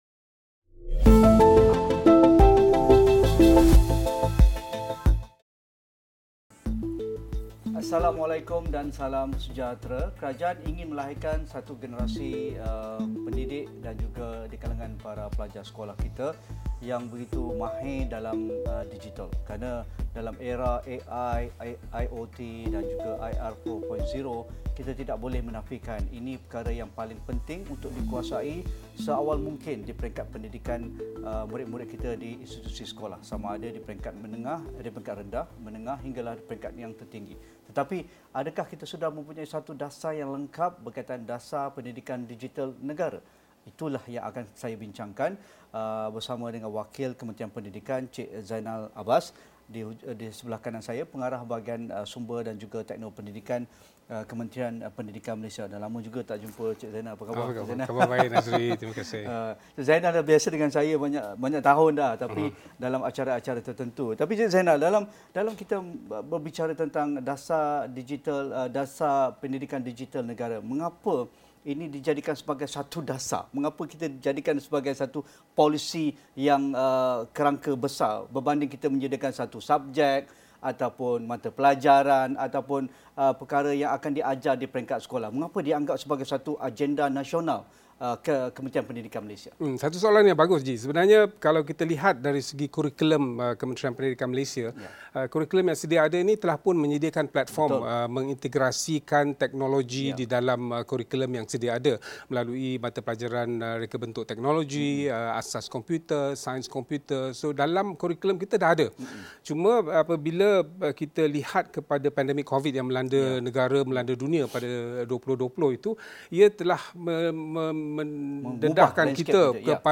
Headliner Embed Embed code See more options Share Facebook X Subscribe Dasar Pendidikan Digital Negara dilaksanakan antara lain bagi memastikan kefasihan digital dalam kalangan guru, murid dan pemimpin sekolah, selain sasaran untuk mengurangkan jurang digital antara sekolah-sekolah tidak kira sekolah agama, sekolah pinggir bandar, sekolah kurang murid. Diskusi 9 malam